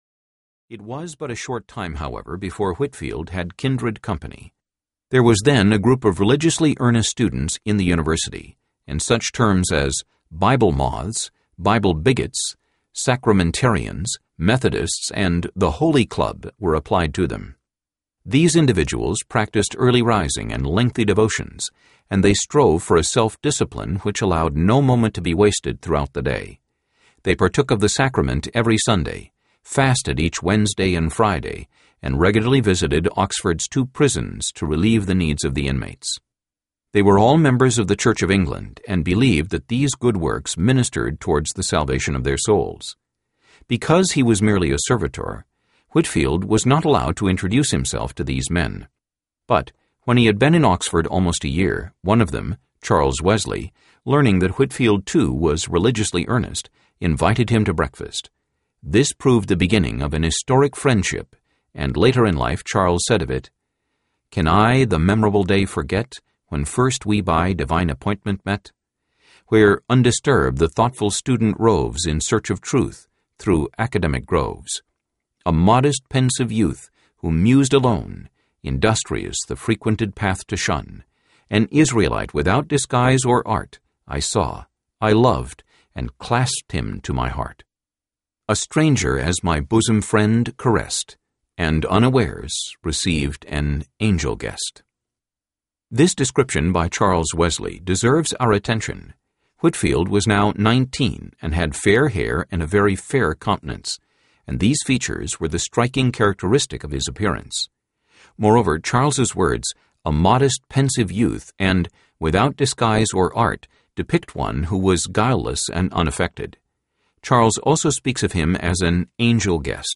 George Whitefield Audiobook
Narrator